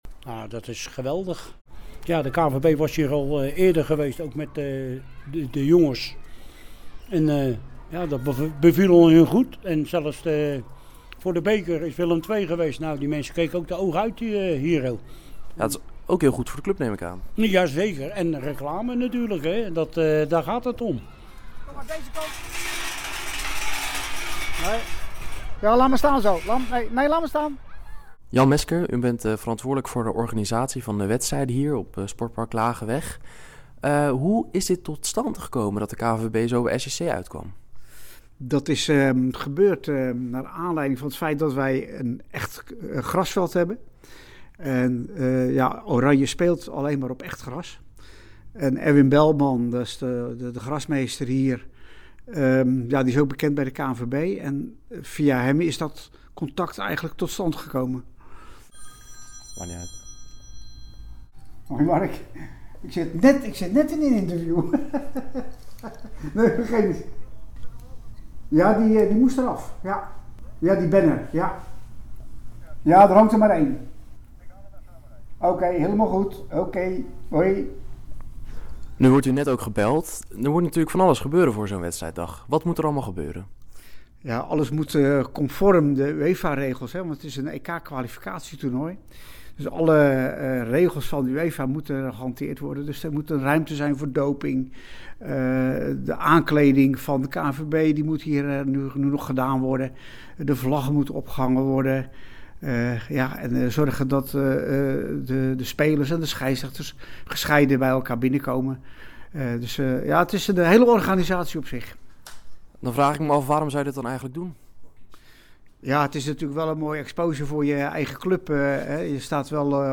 heeft aan de aanwezige vrijwilligers gevraagd wat ze ervan vinden dat de kwalificatieronde onder andere bij SJC wordt gehouden: